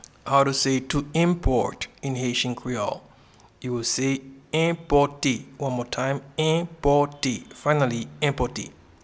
Pronunciation and Transcript:
to-Import-in-Haitian-Creole-Enpote.mp3